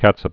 (kătsəp, kăchəp, kĕch-)